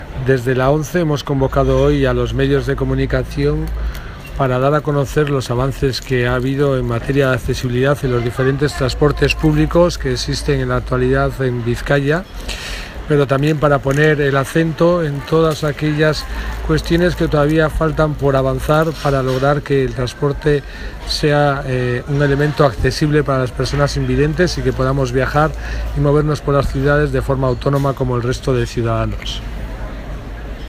presentación del acto (archivo mp3), “esta acción pone en valor los avances que se han dado en materia de accesibilidad, pero debemos subrayar la necesidad de seguir avanzando, porque no se ha logrado la plena accesibilidad e independencia en el transporte público.